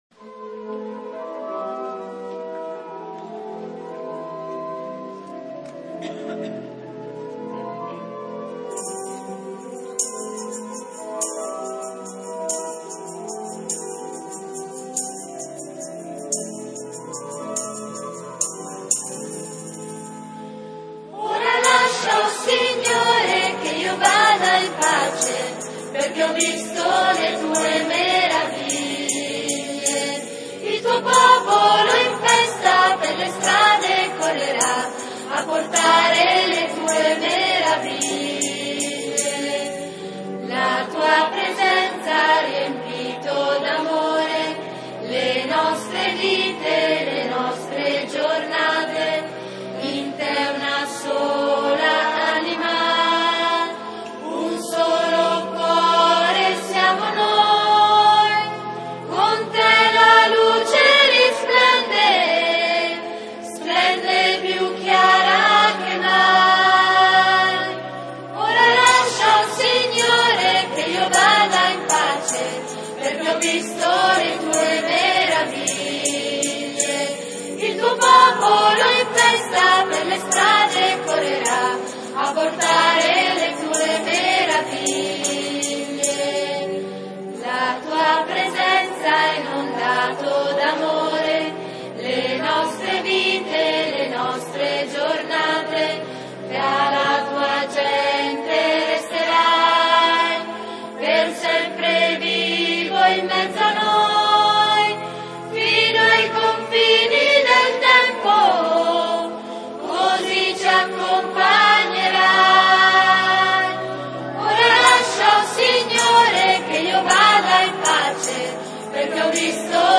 PASQUA DI RESURREZIONE